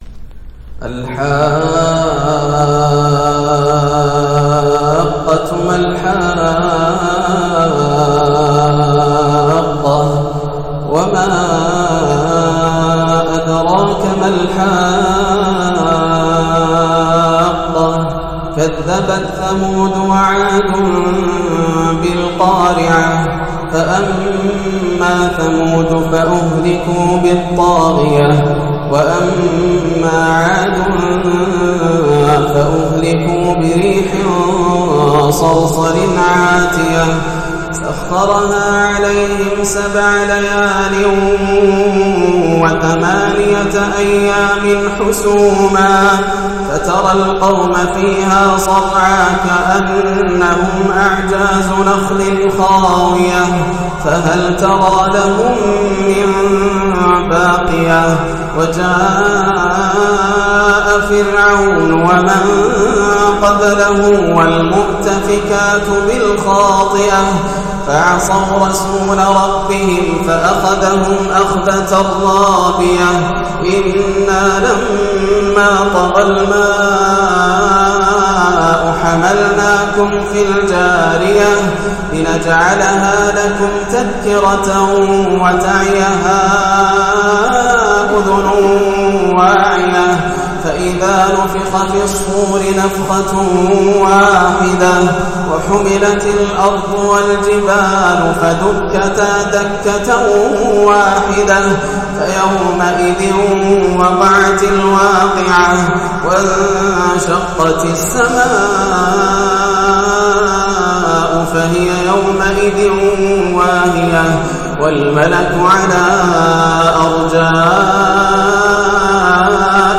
تلاوة لسورتي الحاقة والغاشية | عشاء 4-6-1431 > عام 1431 > الفروض - تلاوات ياسر الدوسري